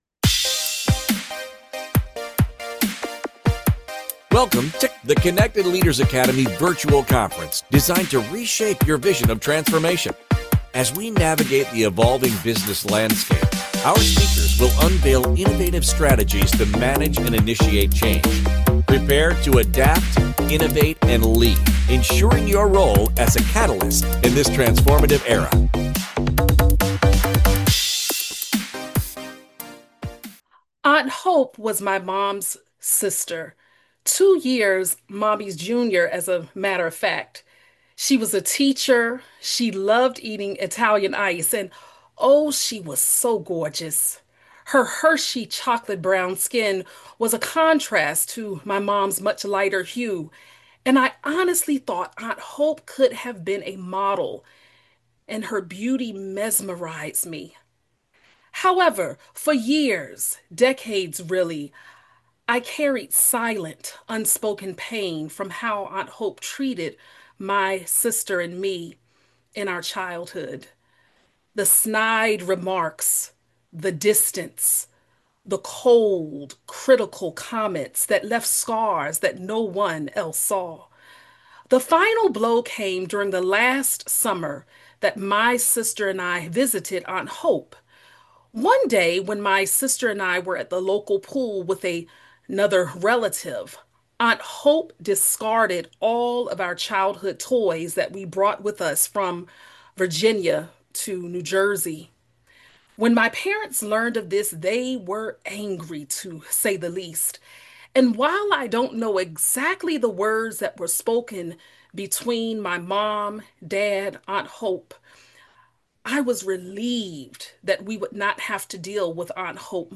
In this powerful speech